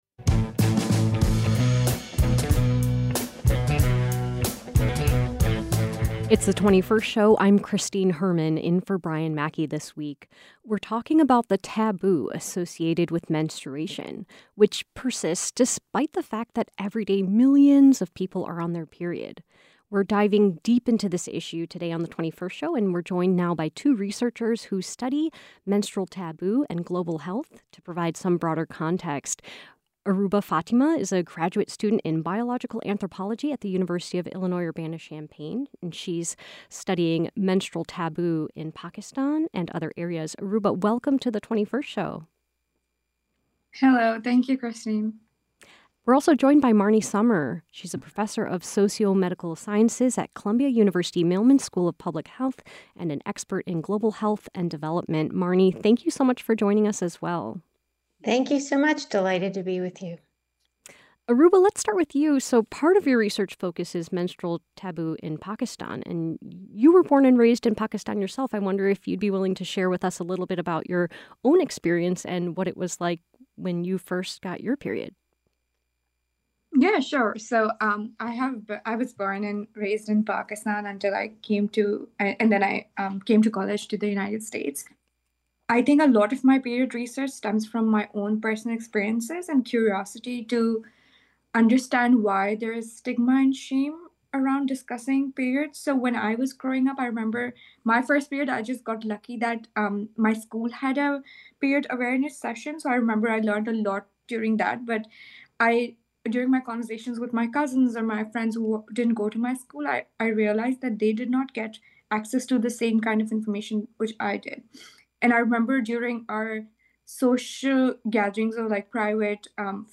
We spoke with two researchers who study the impact of period stigma on girls and women across the globe and consider how the issue plays out in the U.S.